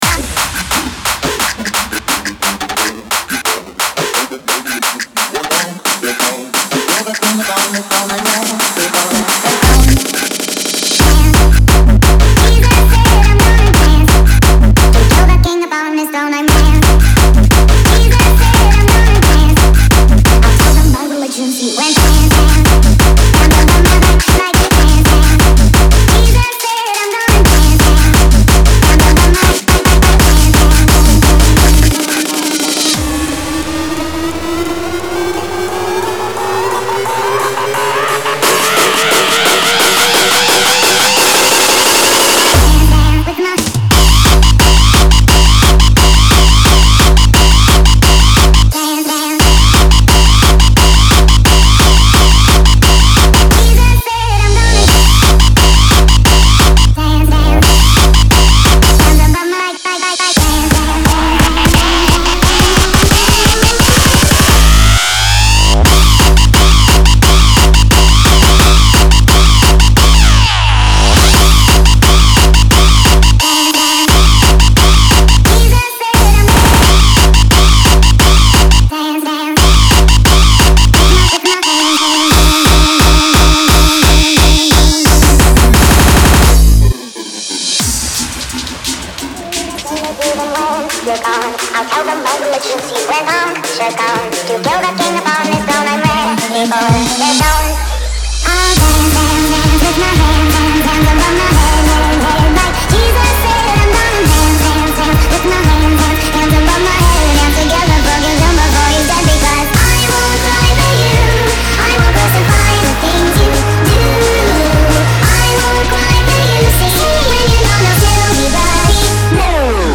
Género: Newstyle.